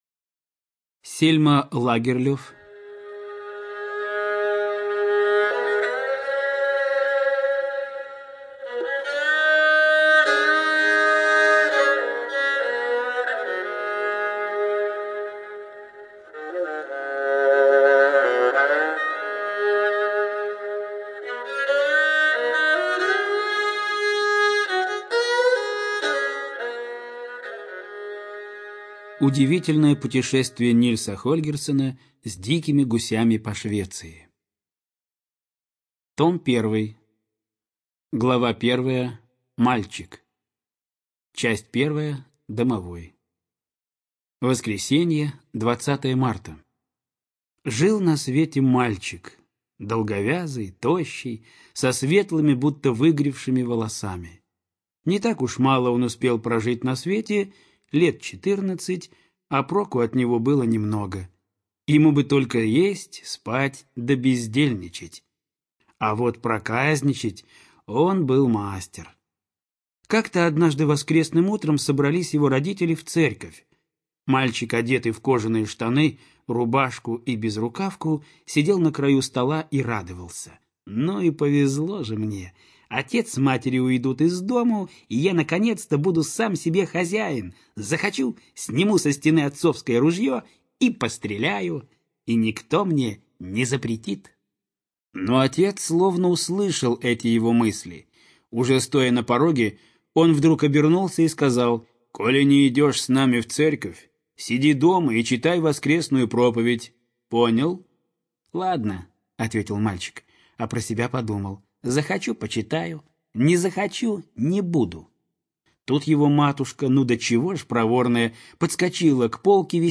ЖанрДетская литература